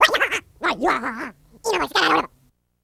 Alien Voices Random, Various Speeds